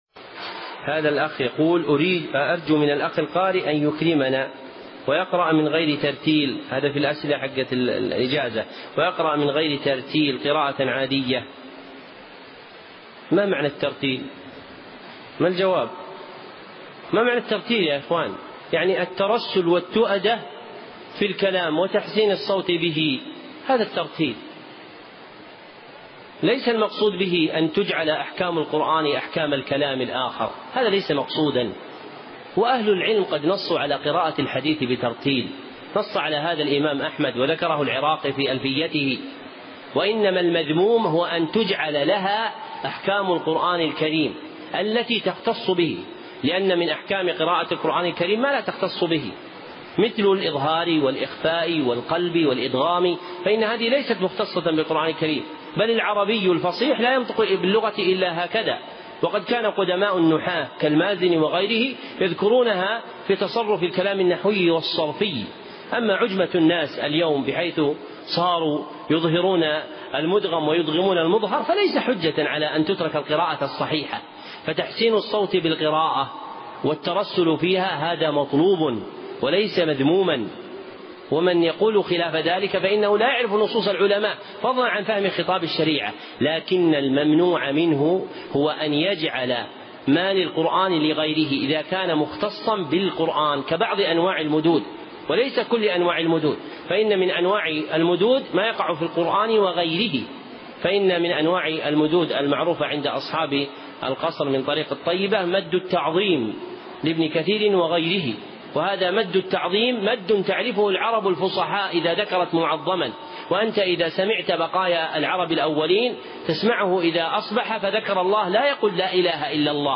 ترتيل المتون